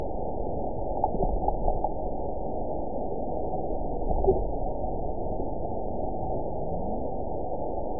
event 911371 date 02/23/22 time 14:27:11 GMT (3 years, 2 months ago) score 8.94 location TSS-AB05 detected by nrw target species NRW annotations +NRW Spectrogram: Frequency (kHz) vs. Time (s) audio not available .wav